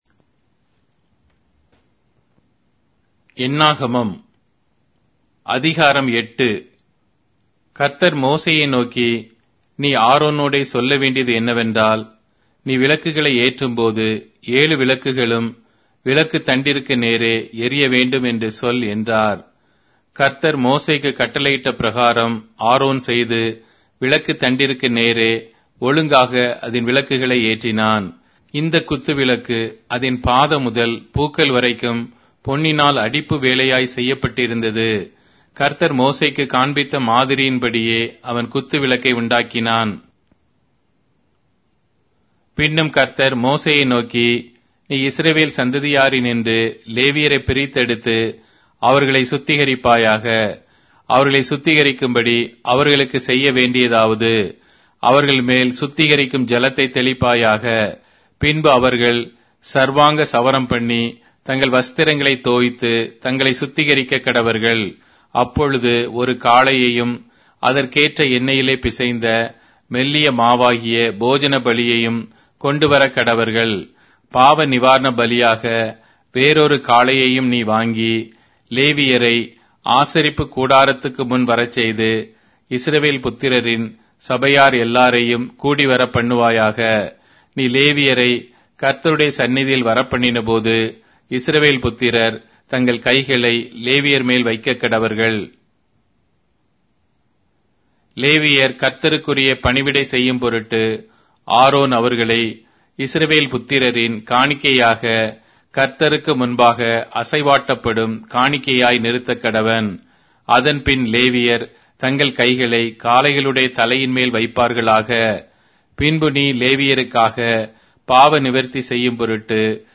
Tamil Audio Bible - Numbers 21 in Ocvhi bible version